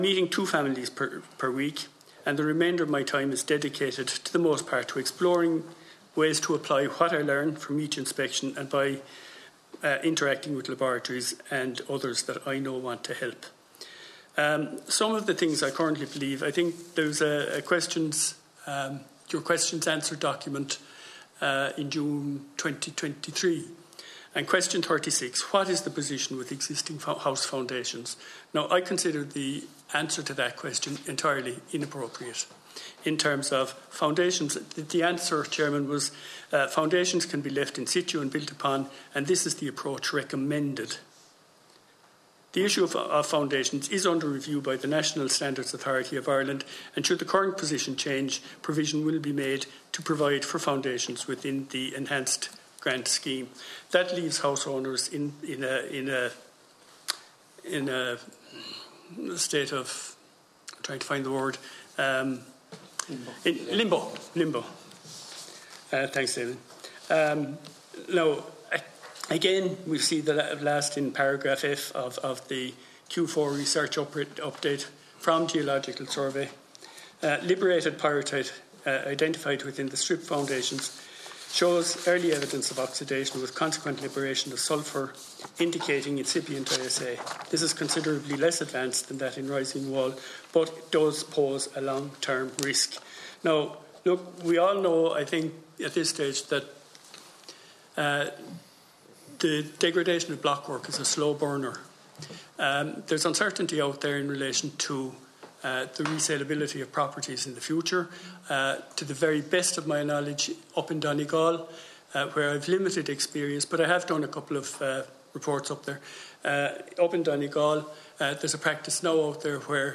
The Oireachtas Finance Committee met this afternoon to discuss issues relating to Defective Concrete Blocks.